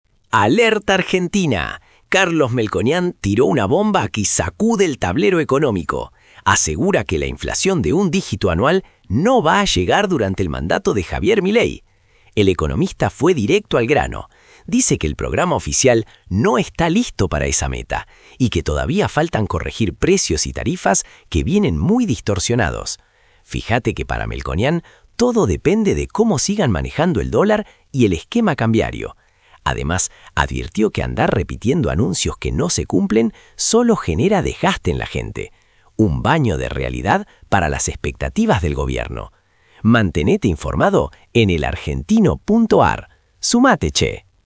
— 🎙 Resumen de audio generado por IA.